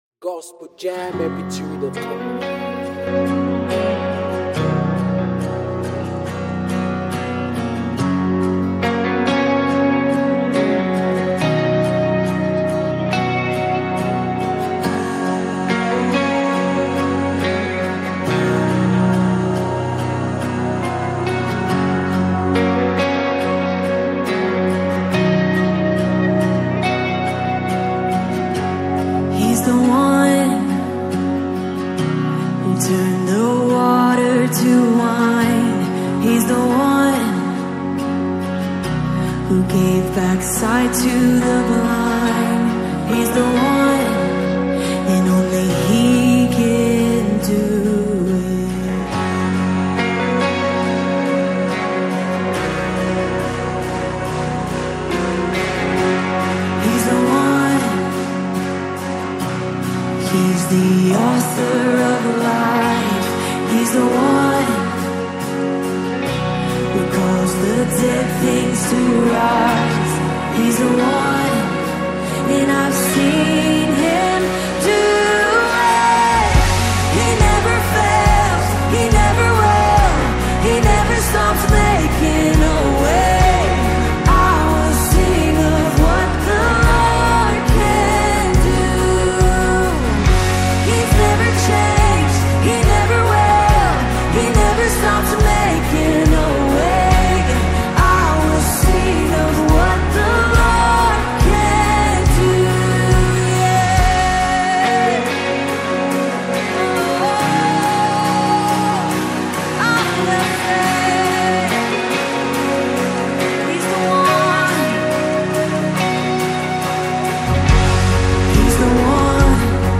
live in Redding, CA